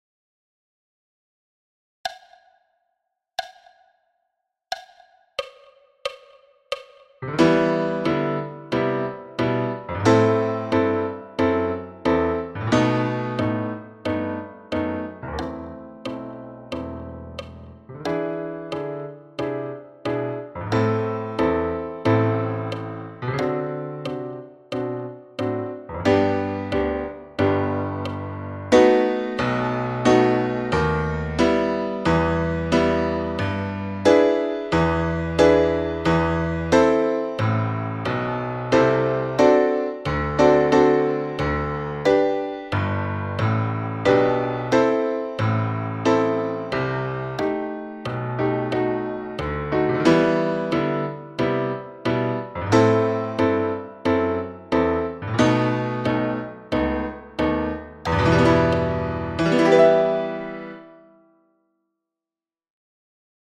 Etude n°5 – Londeix – piano solo à 90 bpm